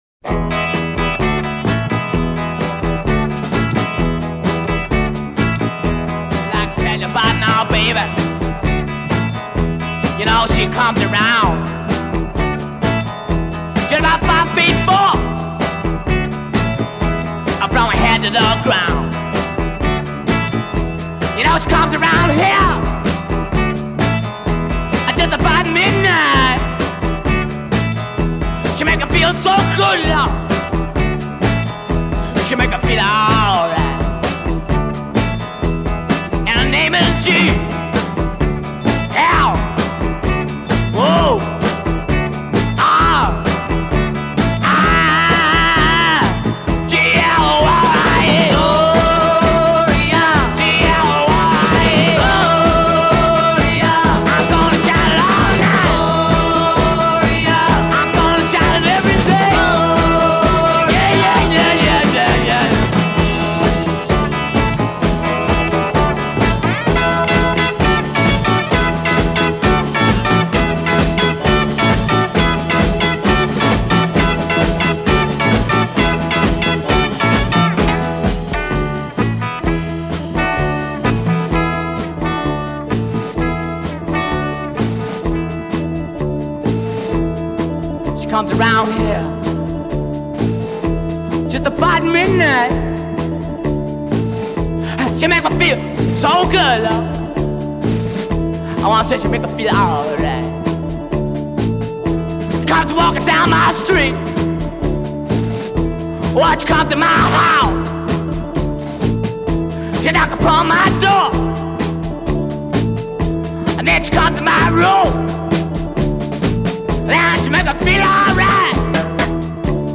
intro 0:00 ensemble vamps (bass with plectrum)
A verse 0: vocal is half-spoken/half-sung a
p2 : add cymbols and responding chorus b'
fill : guitar plays repeated figure
outro : extend refrain part 2, tack on fill and end b